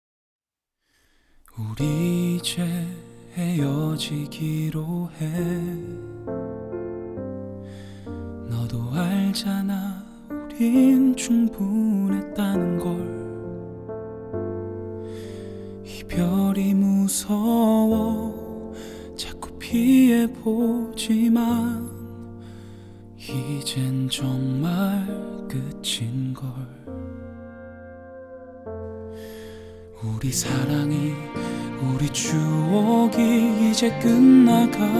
# Korean Indie